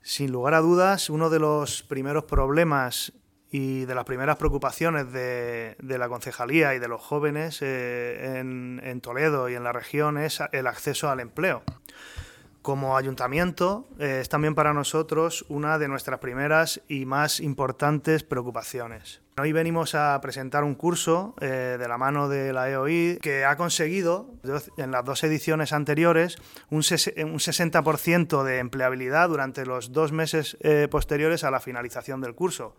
Diego Mejías, concejal de Juventud